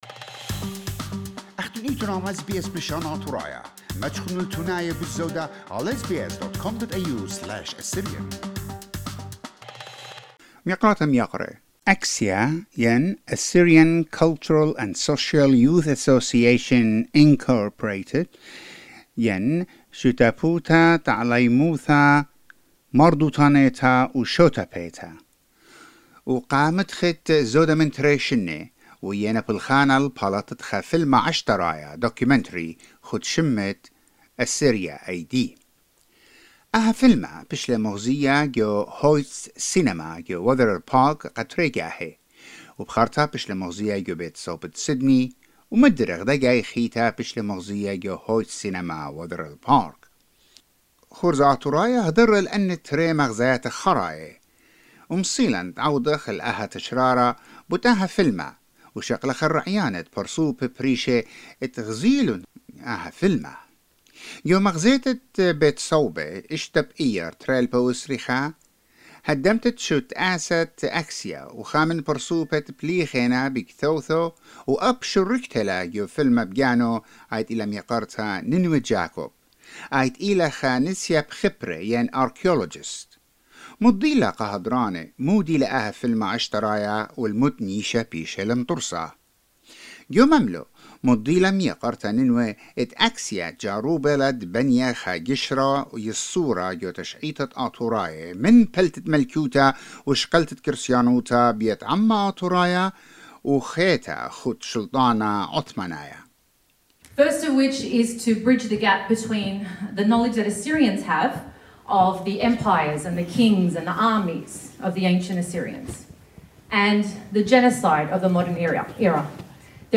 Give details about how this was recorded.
ACSYA.jpg SBS Assyrian spoke with audiences at the screenings to hear their views and reactions to the documentary.